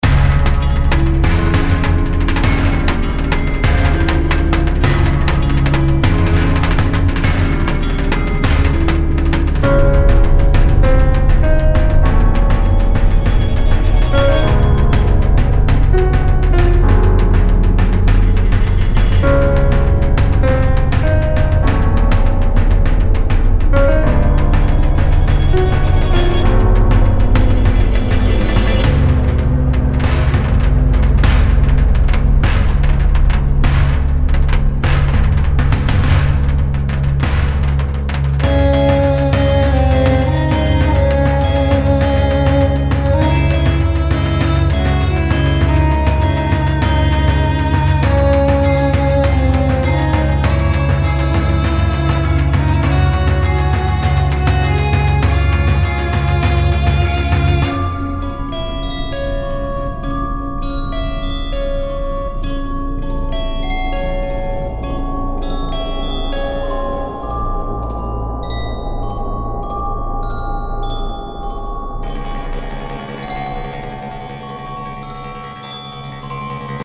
そんなシーンが自然と浮かぶ、ダークで儀式的なムードが特徴です。
静けさの中に潜む緊張感。じわじわと迫るような威圧感。
作曲時は、あえてメロディを控えめにし、“空間”や“質感”にフォーカス。
• テンポ：BPMおよそ60前後のスローな進行
• 拍子：4/4拍子、ゆったりとした脈動感
• 和声：ディミニッシュコードやサスペンデッドコードを多用し、不安定感を演出
- 金属系の打楽器（ガムラン風）で神秘性を表現
- 深くリバーブを効かせたパッドで空間演出
- 時折入る鐘の音が“儀式感”を高める
フリーBGM ダンジョン 不気味 神殿 ダークファンタジー 火山